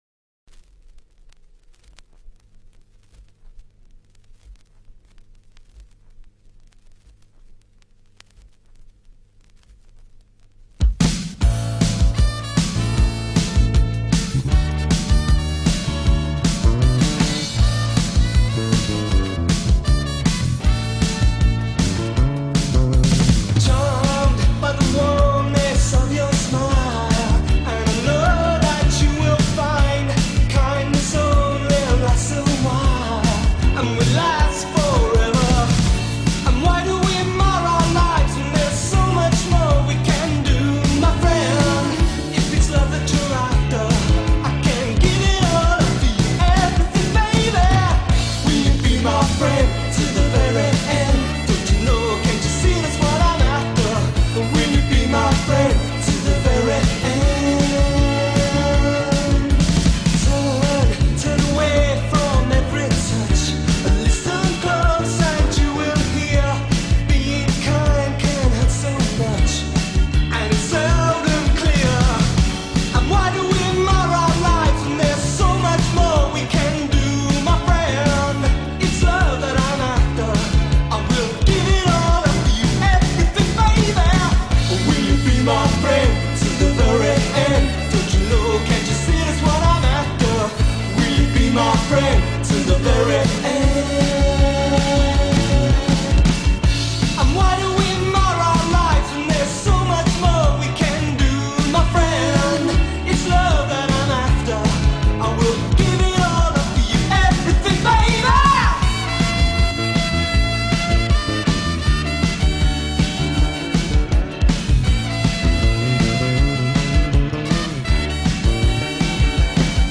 guitar, vocals
bass
keyboards
trumpet
drums